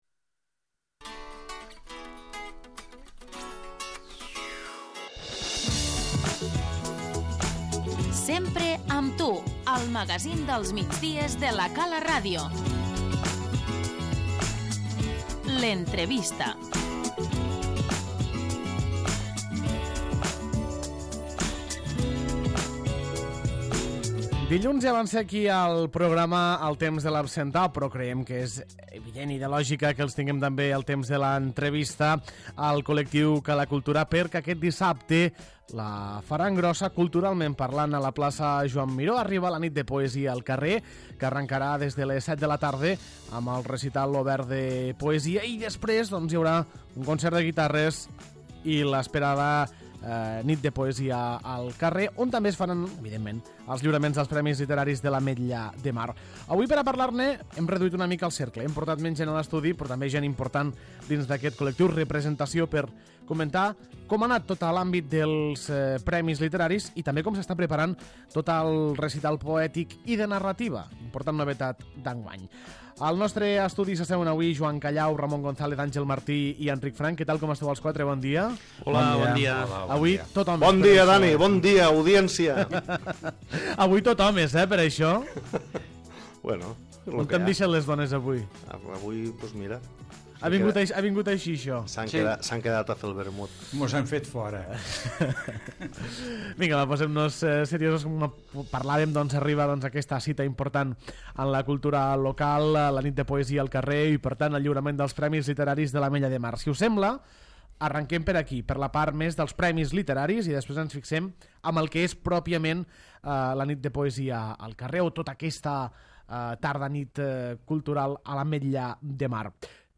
L'entrevista - Nit de Poesia al carrer